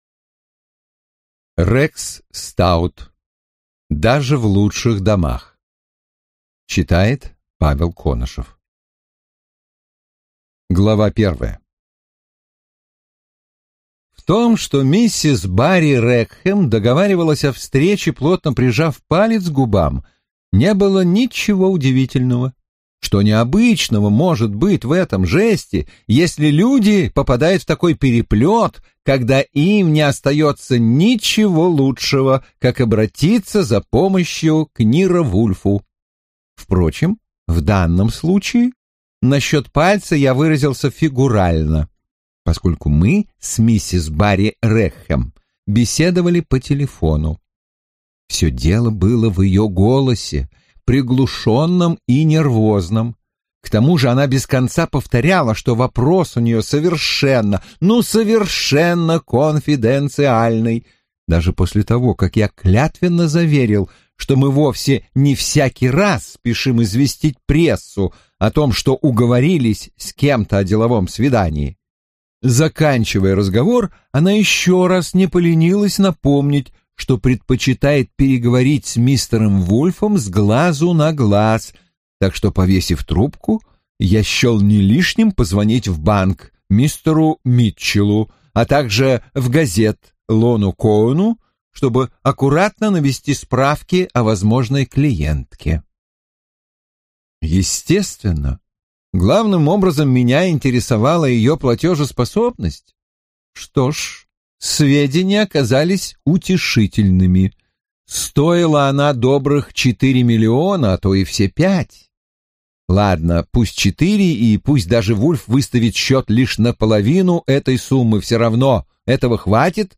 Аудиокнига Даже в лучших домах | Библиотека аудиокниг